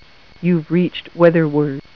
Weather Word connects to a standard telephone line, can run on batteries, and answers the phone with a pleasing female voice
Typical Telephone Answer: